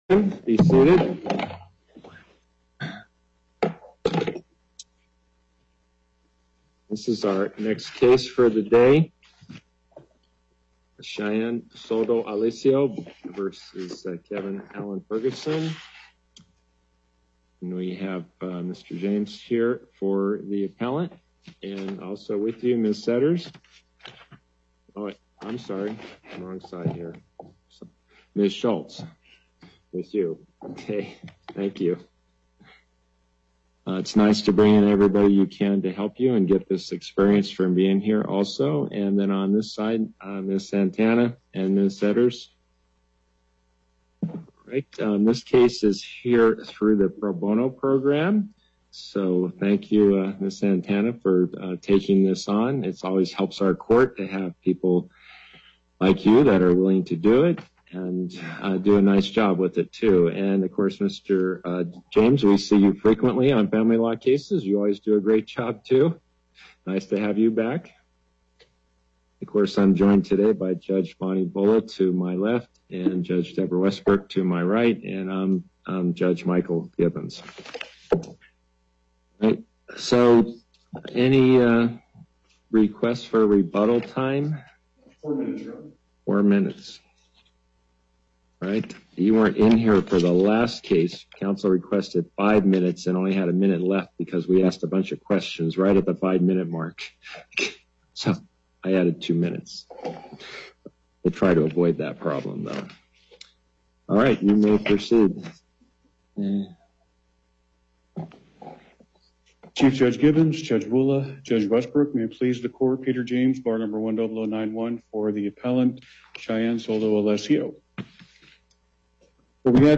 Location: Las Vegas Before the Court of Appeals, Chief Judge Gibbons presiding
as counsel for Appellant
as counsel for Respondent